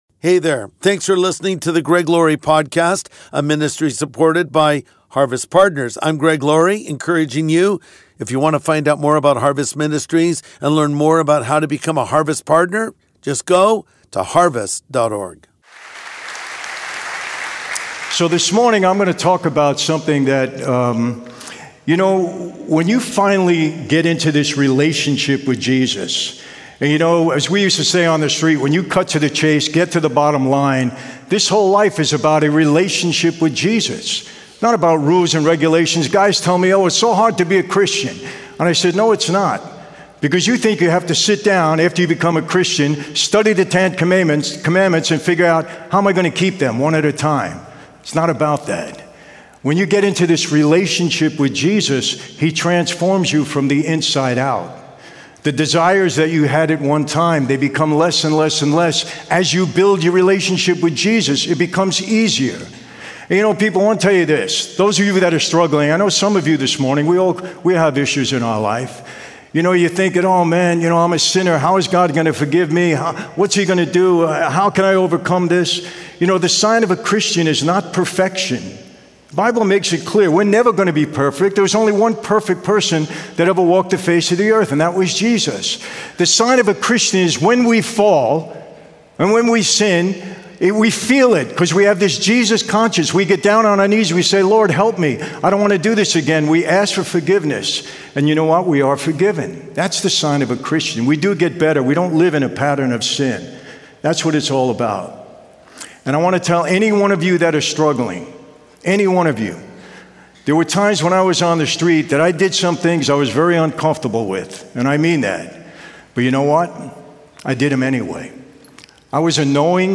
Defining Moments In Our Lives | Sunday Message (Guest Speaker: Michael Franzese)